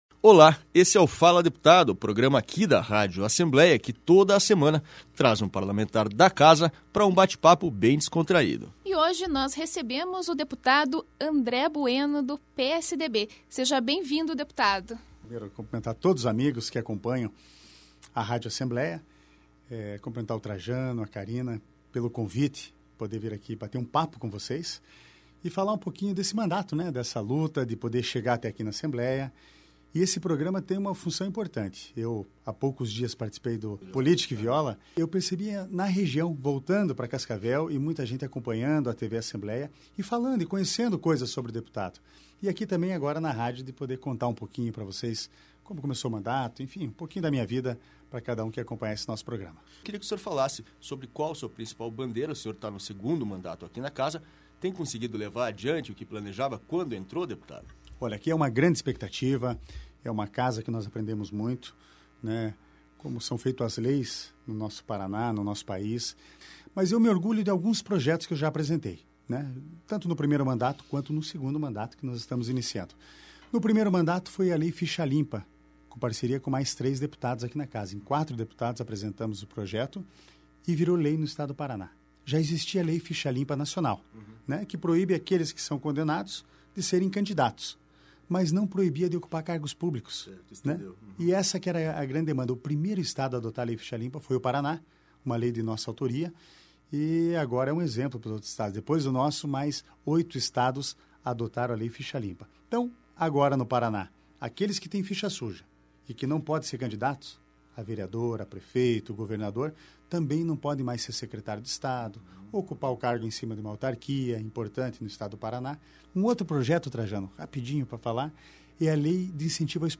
E por falar em som e em boas lembranças, o deputado contou, durante o bate papo, que, bem cedo também começou a trabalhar com o pai na famosa loja de discos “Discolãndia”, sucesso de público e crítica na região Oeste do Paraná.